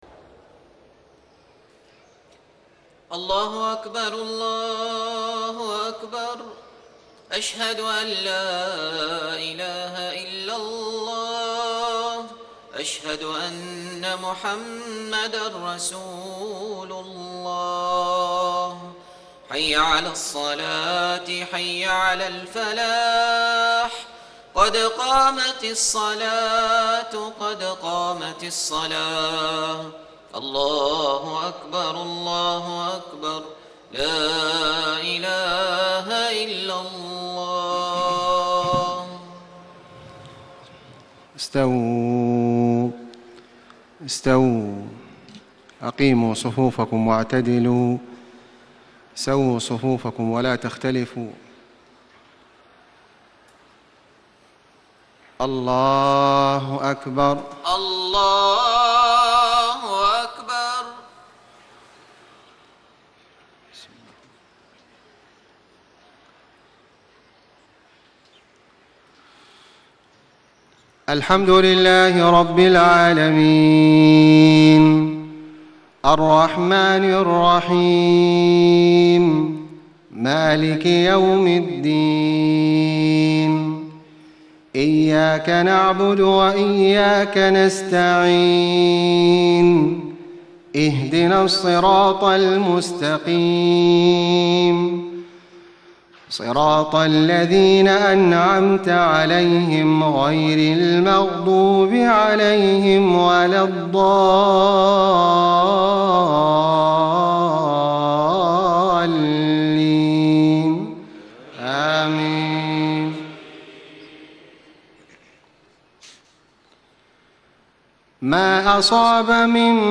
صلاة العشاء 4-5-1435 سورة التغابن > 1435 🕋 > الفروض - تلاوات الحرمين